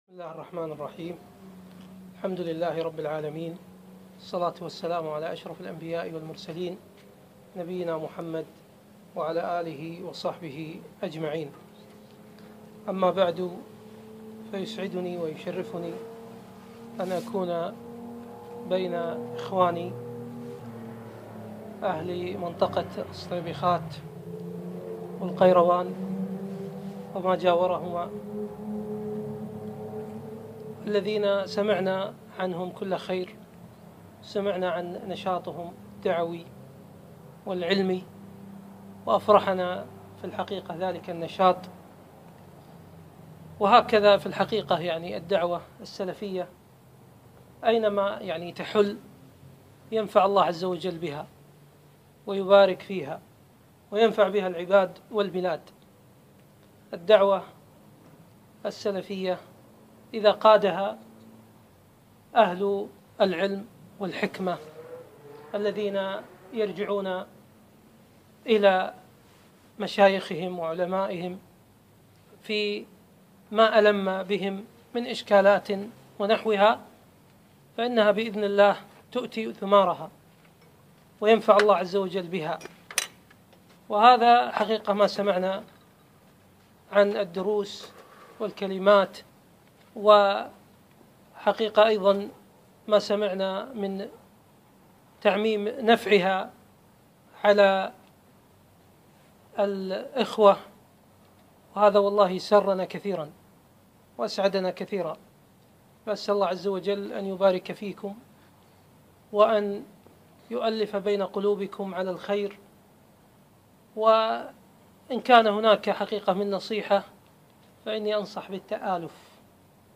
محاضرة - الدعوة السلفية في مواجهة التحديات